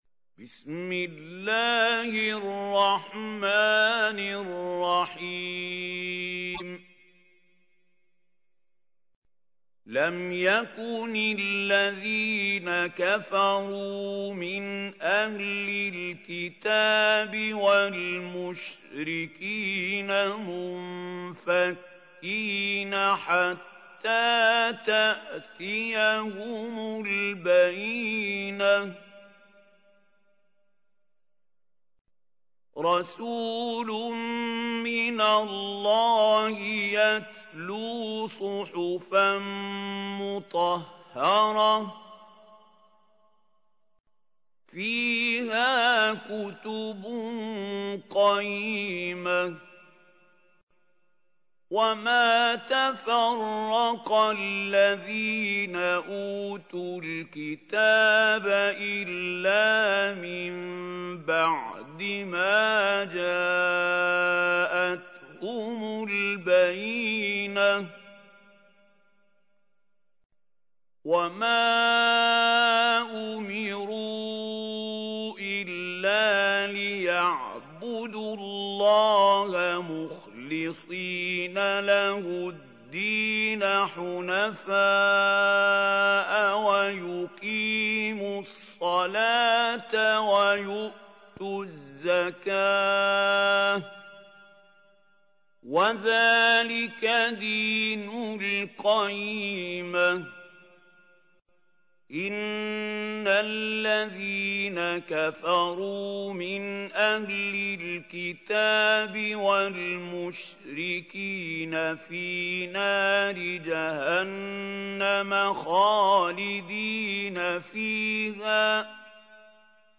تحميل : 98. سورة البينة / القارئ محمود خليل الحصري / القرآن الكريم / موقع يا حسين